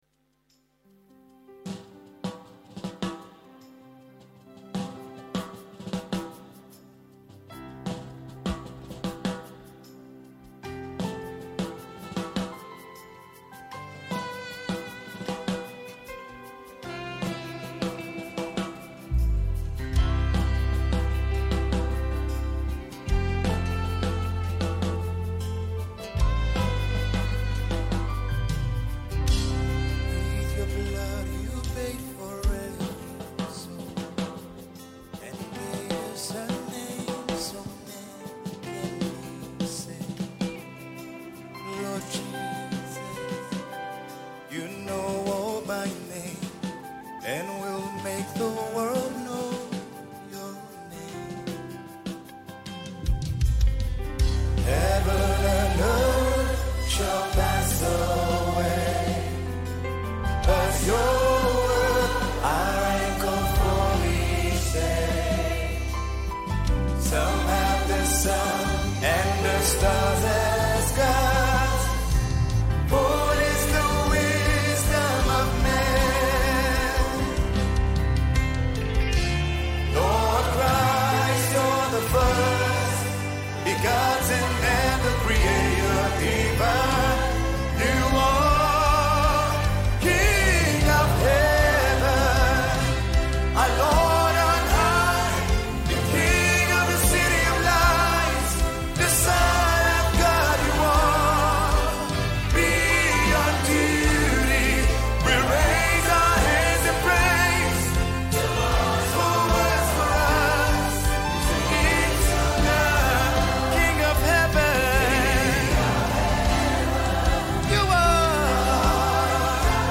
u sing so passionately